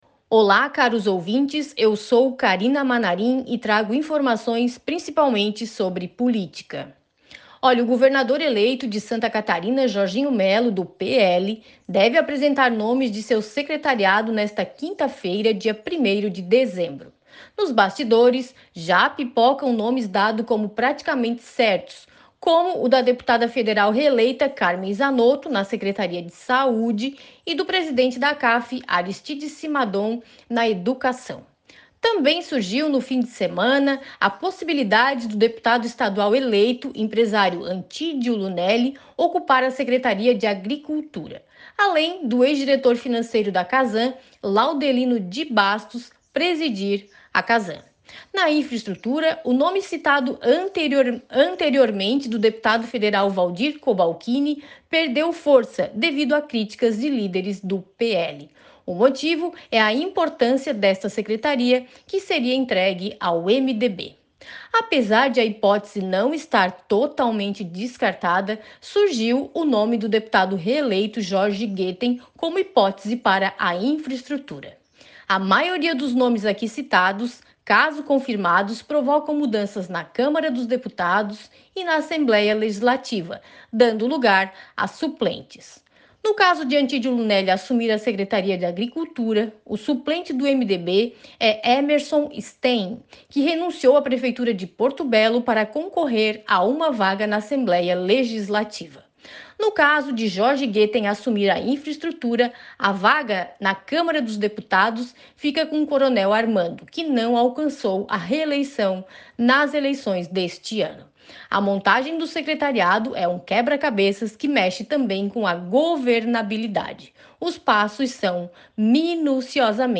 Jornalista comenta sobre a data da apresentação dos possíveis nomes para preencher cargos importantes para o futuro governo de SC